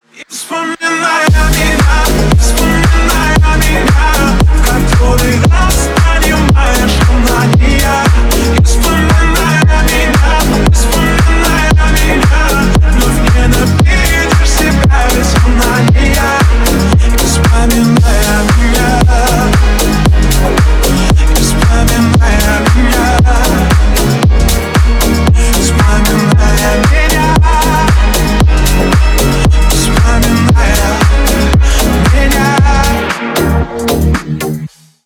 бесплатный рингтон в виде самого яркого фрагмента из песни
Ремикс
клубные